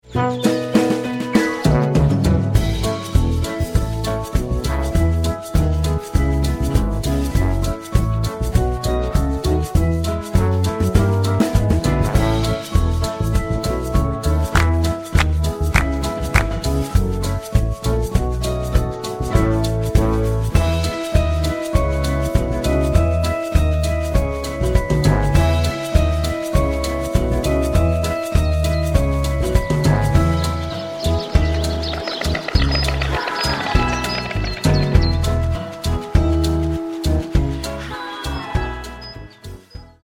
Wersja instrumentalna bez chórków i linii melodycznej: